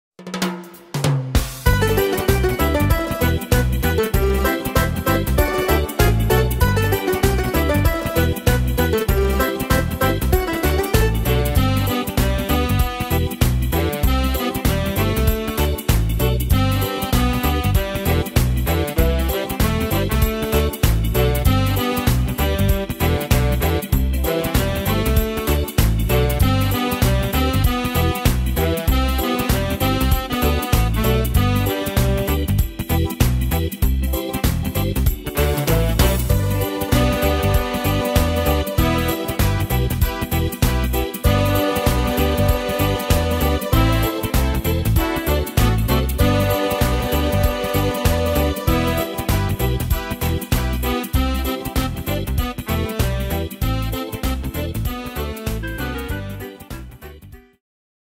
Tempo: 97 / Tonart: G-Dur